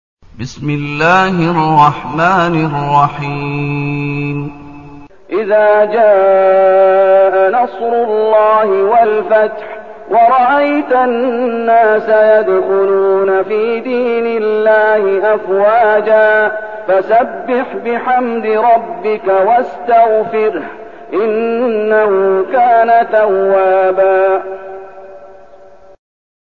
المكان: المسجد النبوي الشيخ: فضيلة الشيخ محمد أيوب فضيلة الشيخ محمد أيوب النصر